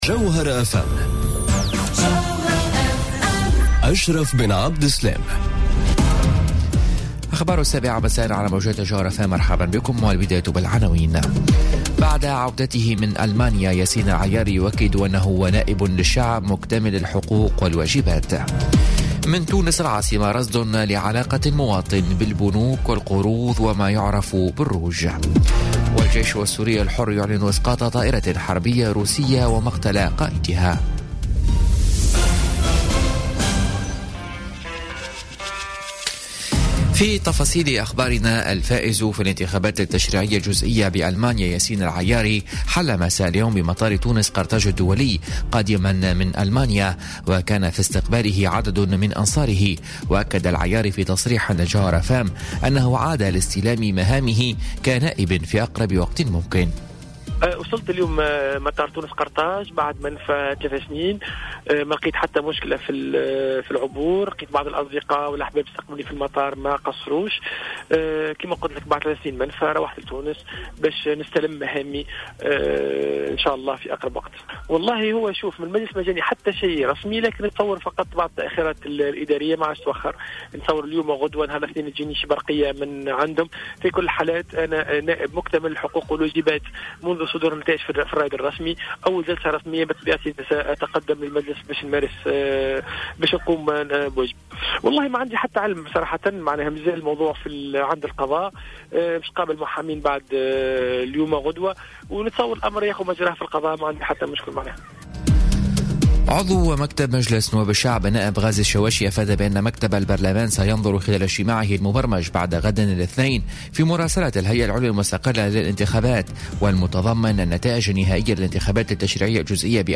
نشرة أخبار السابعة مساء ليوم السبت 3 فيفري 2018